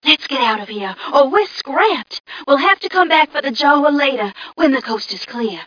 mission_voice_m1ca029.mp3